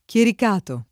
chiericato
chiericato [ k L erik # to ]